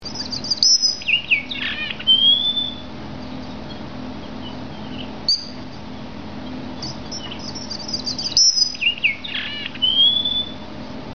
Both sexes sing, though the hen doesn't trill as loudly.
Song
Red Avadavats have a beautiful flute-like song: